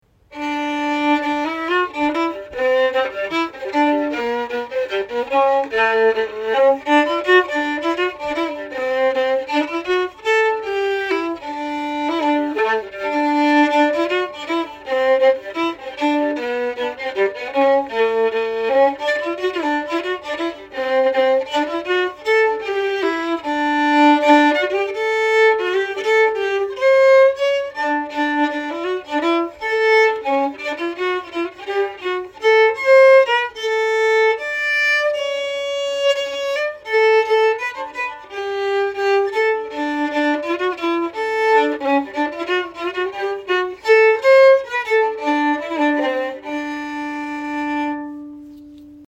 Key: Dm
Form: Reel
M:4/4